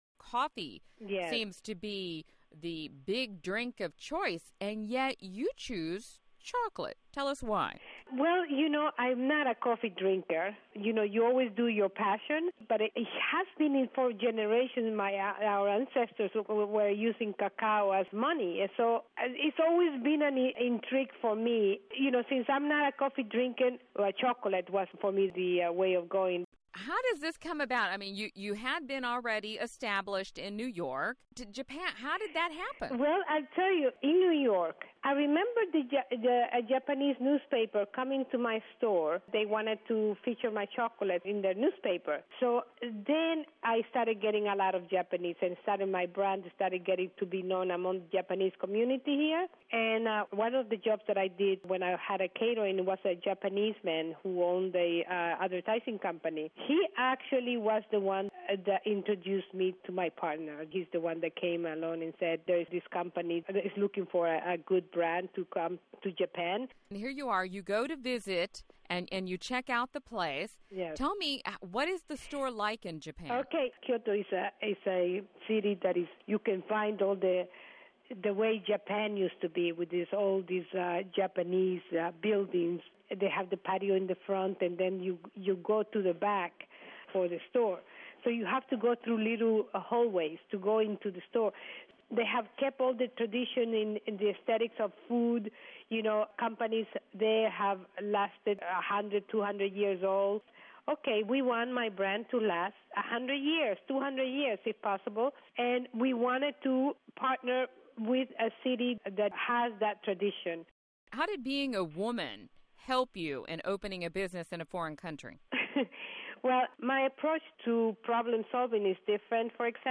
Q&A: Bringing Gourmet Chocolate to Kyoto, Japan